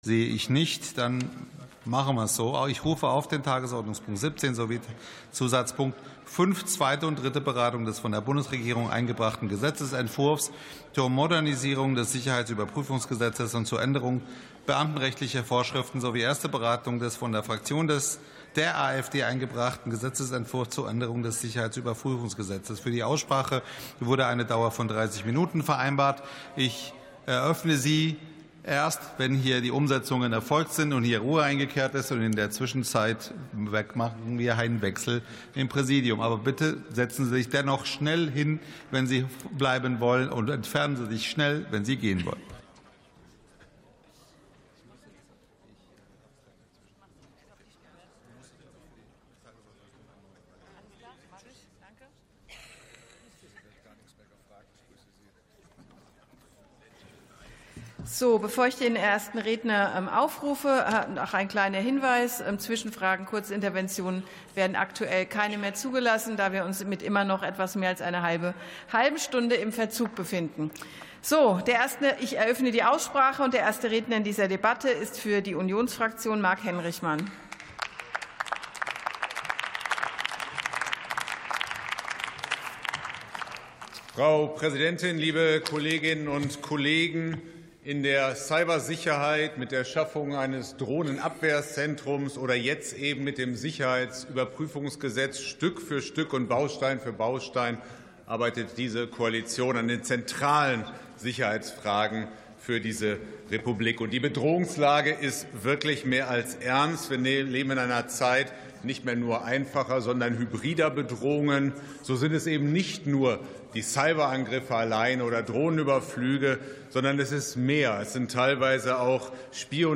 47. Sitzung vom 04.12.2025. TOP 17, ZP 5: Modernisierung des Sicherheitsüberprüfungsgesetzes ~ Plenarsitzungen - Audio Podcasts Podcast